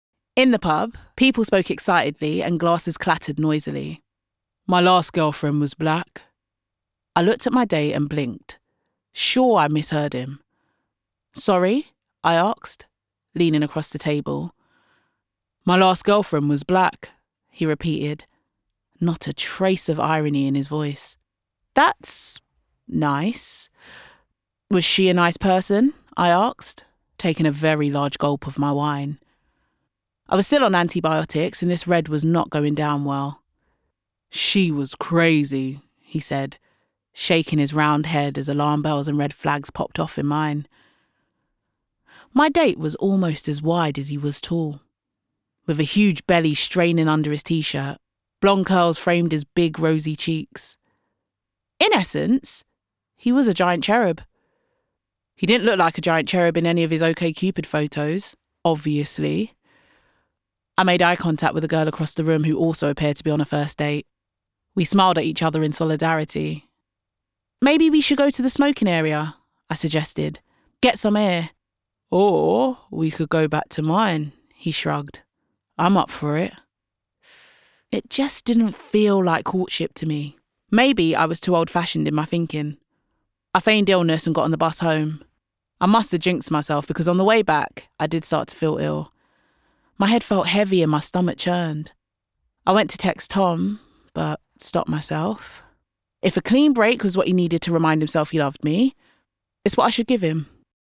• Female
• London